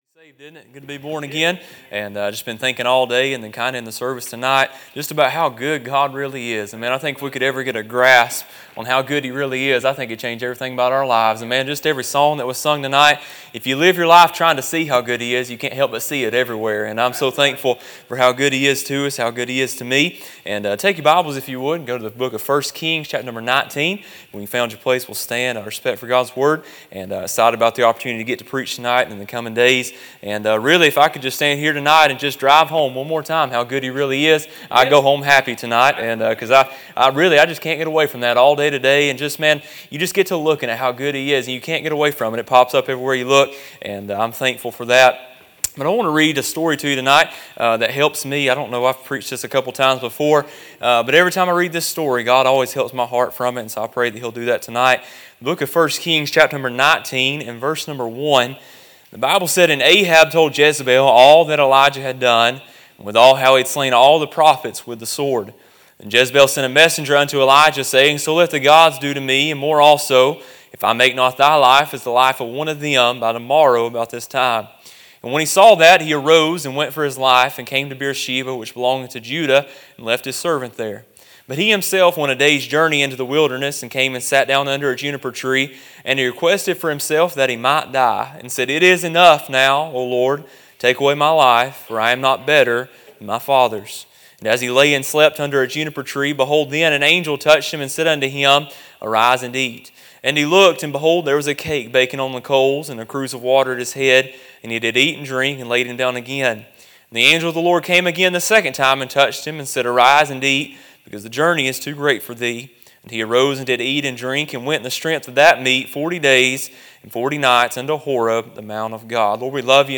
A sermon preached Wednesday Evening, on March 4, 2026.